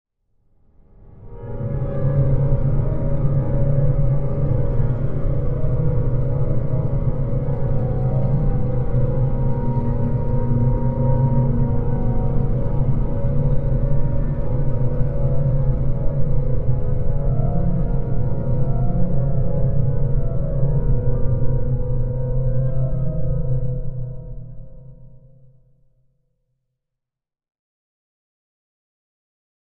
Music: Eerie Piano Tinkles, With Rain Stick Noise.